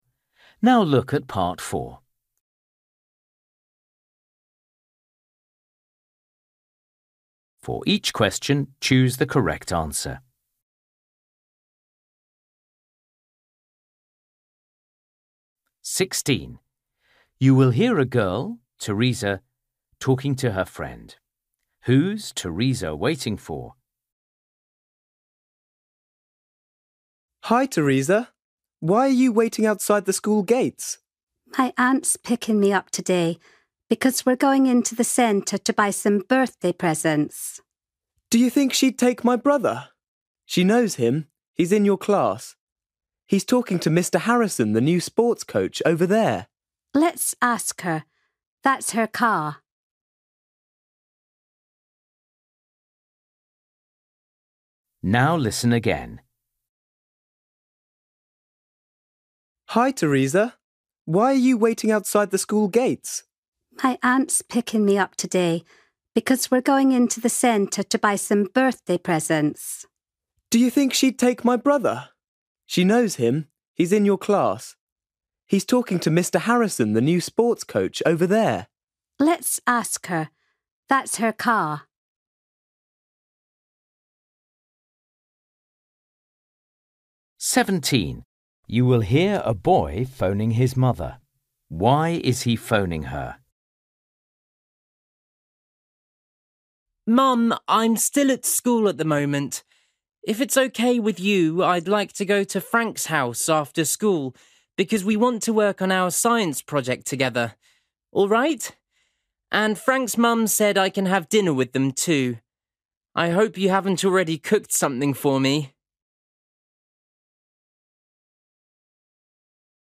17   You will hear a boy phoning his mother.
18   You will hear two friends talking about a new café.